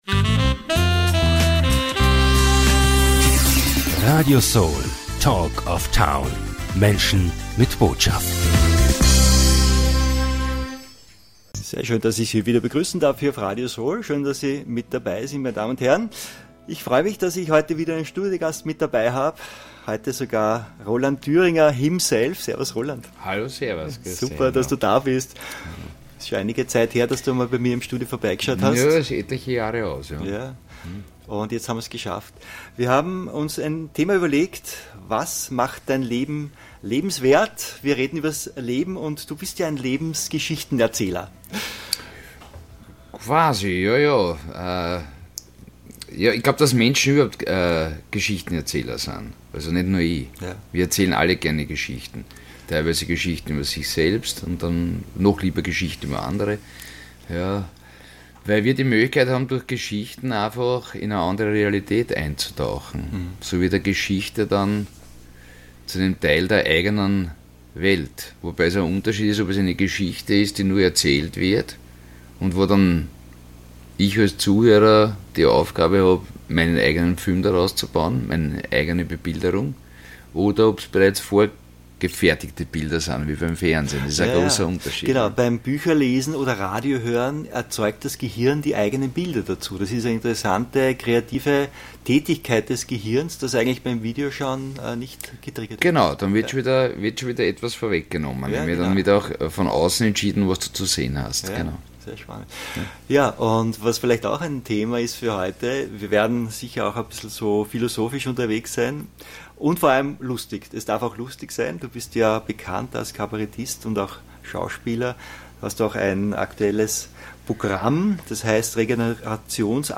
In diesem Interview spricht der Schauspieler und Kabarettist Roland Düringer über die Kraft von Geschichten und über Menschen mit Schmäh.